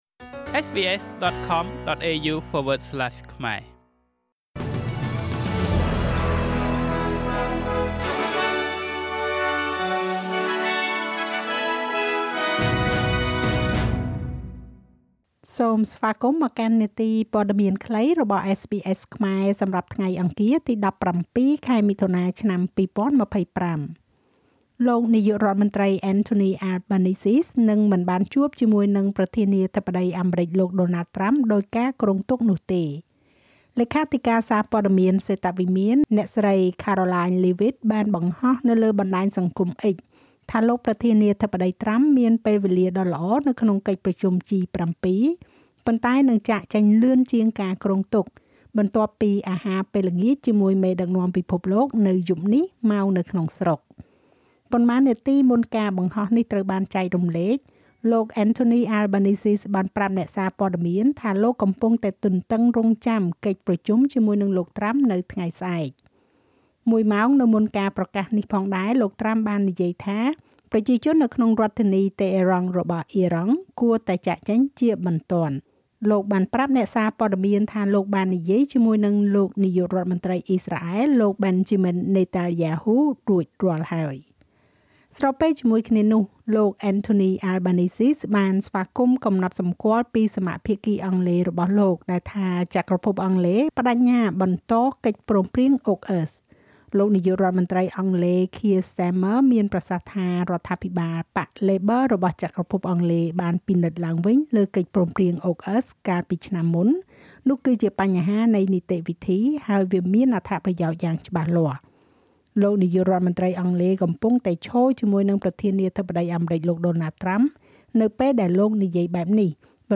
នាទីព័ត៌មានខ្លីរបស់SBSខ្មែរ សម្រាប់ថ្ងៃអង្គារ ទី១៧ ខែមិថុនា ឆ្នាំ២០២៥